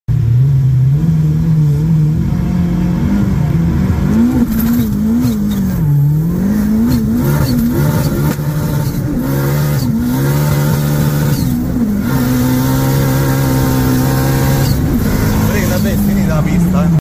Fiat Uno Turbo 1600 con sound effects free download
Fiat Uno Turbo 1600 con turbo G30, 440 cavalli in mappa 2! 🚗💨 Per la prima volta testiamo le prime partenze!